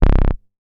MoogAgressPulseC.WAV